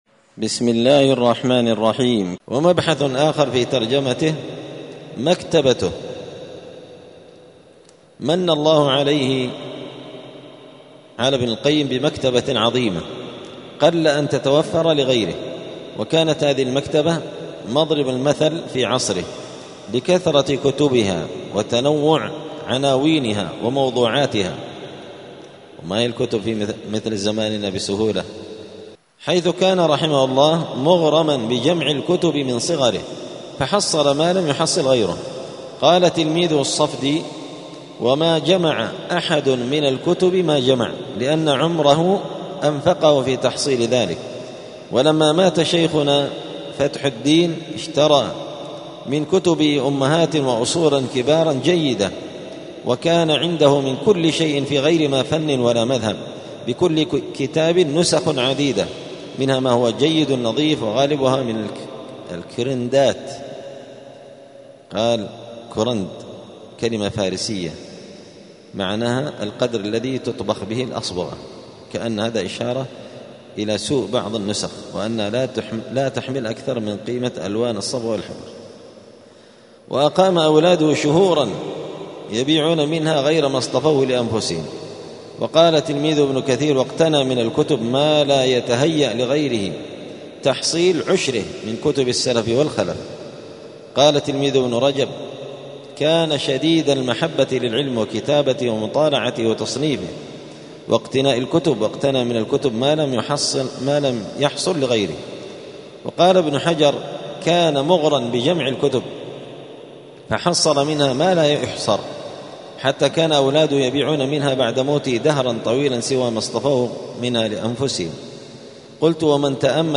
*الدرس السابع (7) {مكتبته ومؤلفاته}*